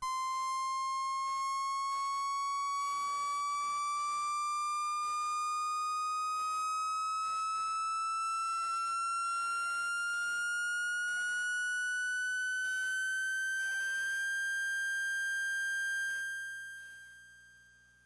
标签： F6 MIDI音符-90 Sequntial-MAX 合成器 单票据 多重采样
声道立体声